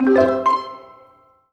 happy_collect_item_02.wav